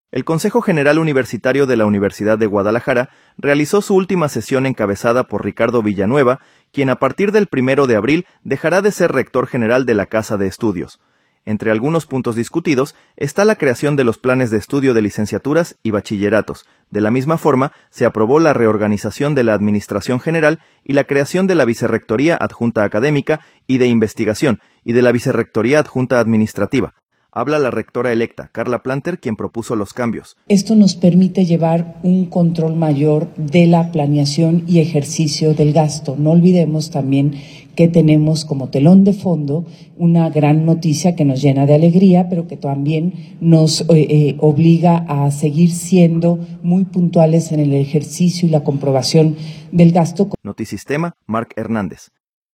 De la misma forma, se aprobó la reorganización de la administración general y la creación de la Vicerrectoría Adjunta Académica y de Investigación; y de la Vicerrectoría Adjunta Administrativa. Habla la rectora electa, Karla Planter, quien propuso los cambios.